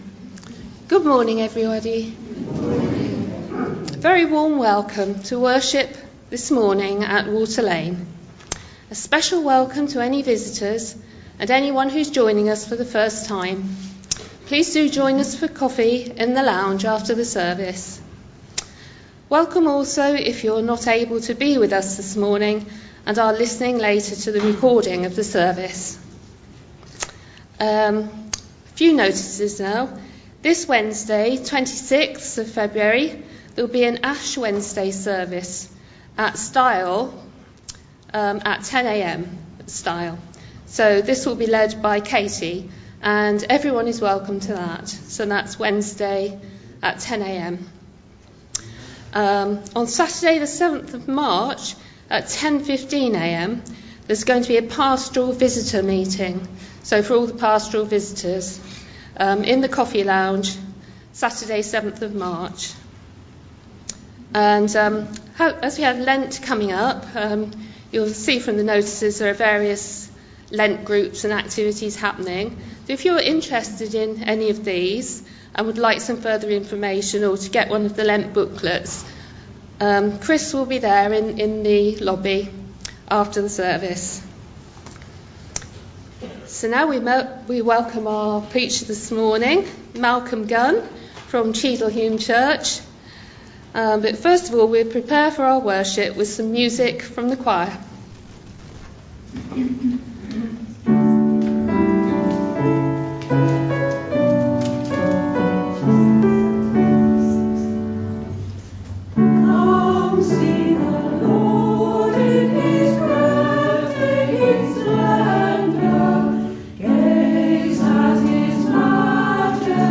2020-02-23 Morning Worship
Genre: Speech.